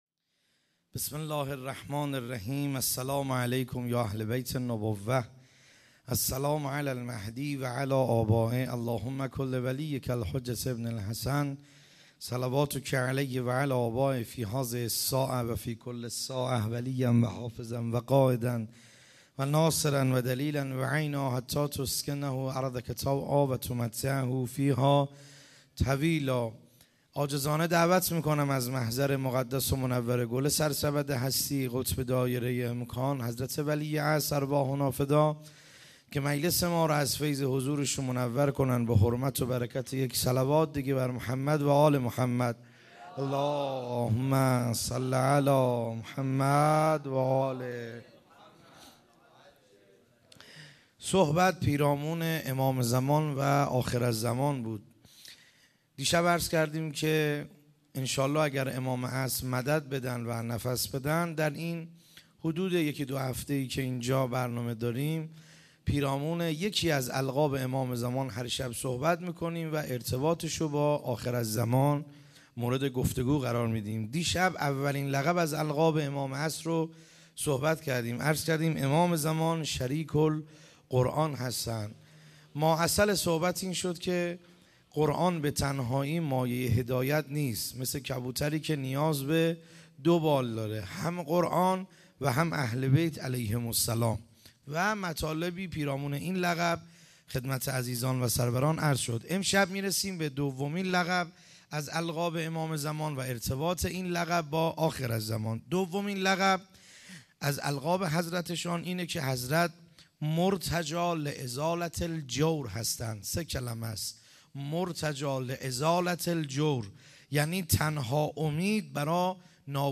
خیمه گاه - بیرق معظم محبین حضرت صاحب الزمان(عج) - سخنرانی ا شب دوم